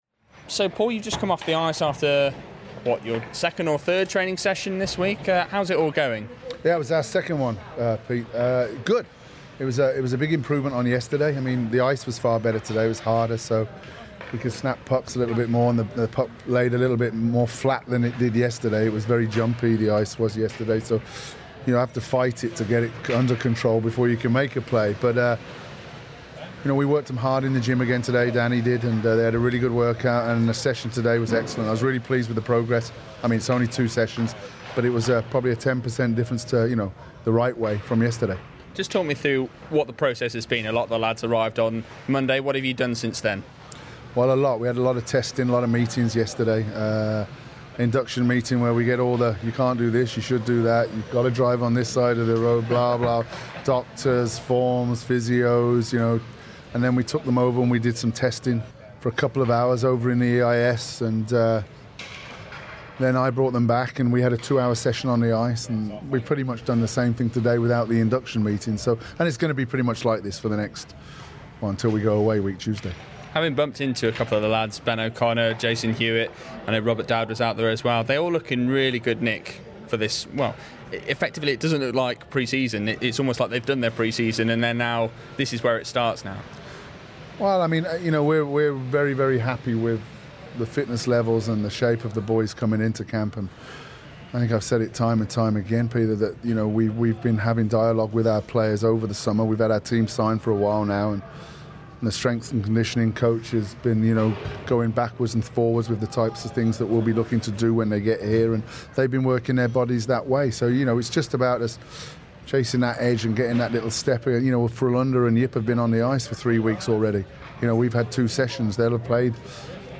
Football Heaven / INTERVIEW